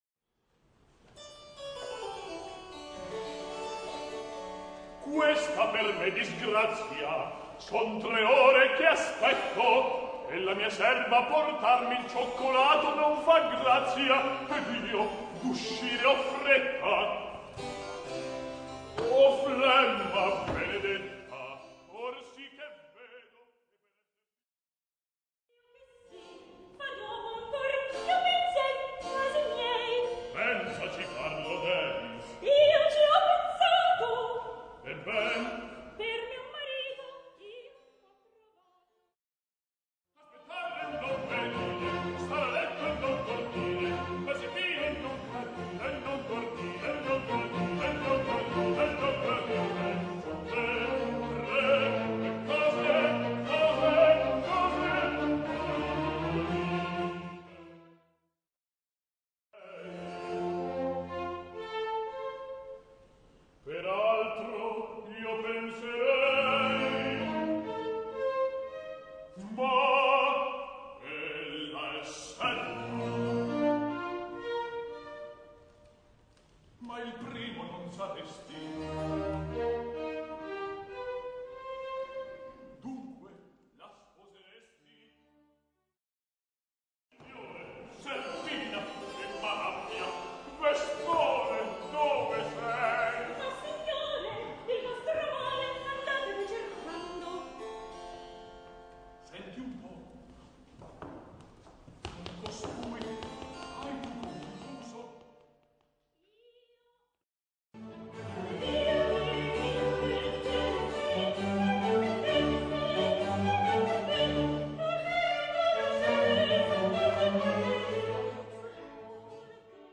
L'ascolto propone in successione frammenti di recitativo (R) o di aria (A).
recitativo_aria.mp3